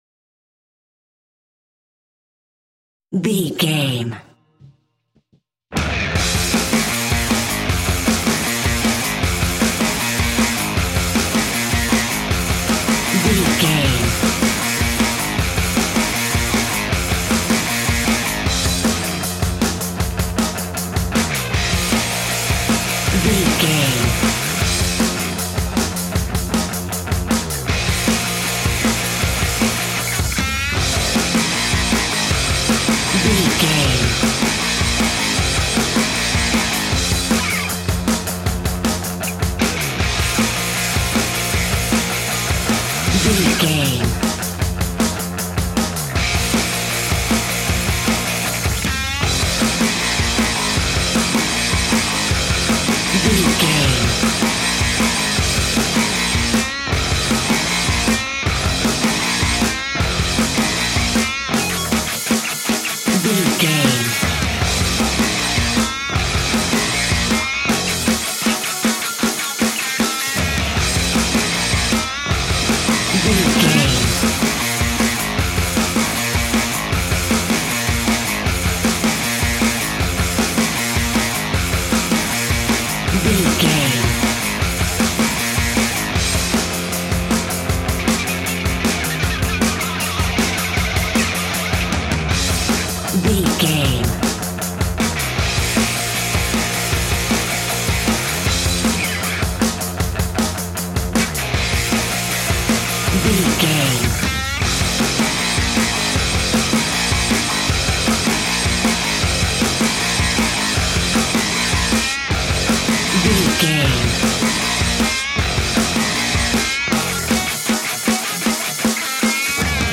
Epic / Action
Aeolian/Minor
hard rock
heavy metal
blues rock
distortion
instrumentals
rock guitars
Rock Bass
heavy drums
distorted guitars
hammond organ